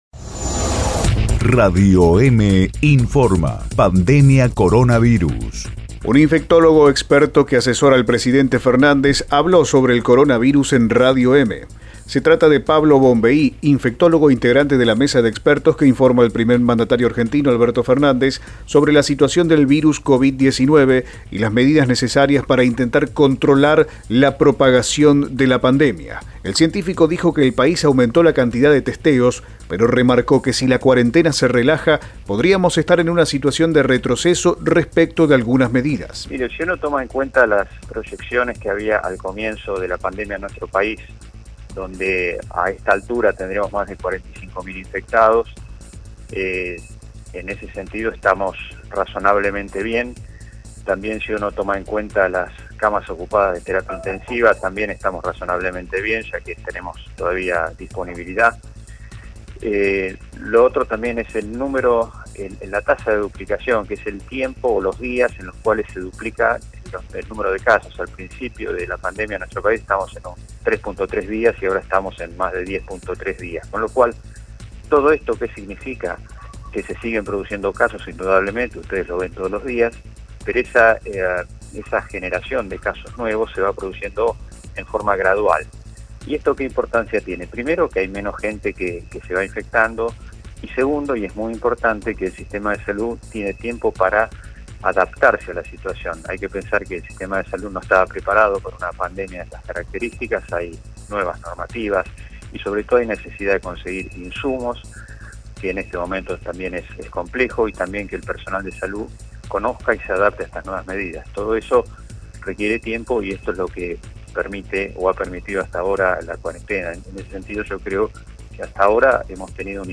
En dialogo con Radio EME, el científico dijo que el país aumentó la cantidad de testeos pero remarcó que si la cuarentena se relaja podríamos estar en una situación de retroceso respecto de algunas medidas.